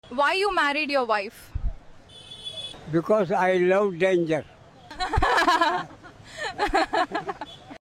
POV: Street interviewer asks an old man why he married his wife 💍 … and without hesitation he drops: “Because I love danger.” 💀😂 AI skits always manage to capture that savage grandpa energy — decades of marriage compressed into one brutally honest punchline.